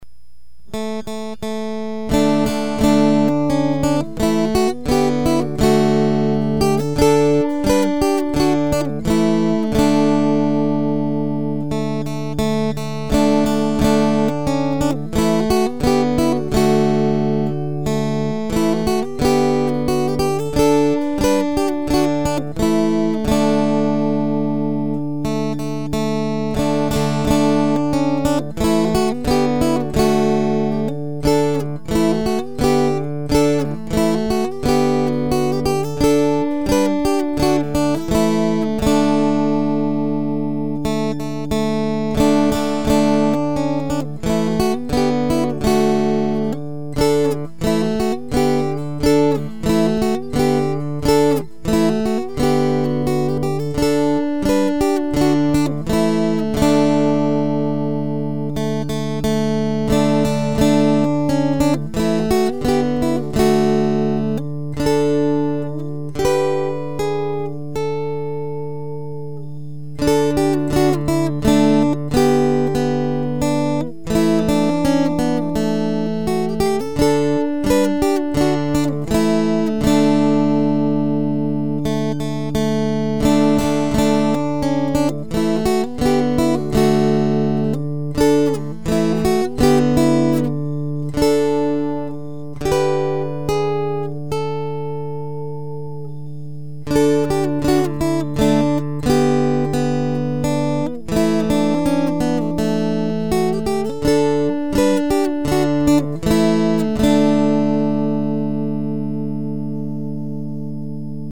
Dulcimer
Because of time limitations, on the sound clip, I only play verses 1 through 6.  There are many repeats, and there are a some minor variations in verses 1 through 5.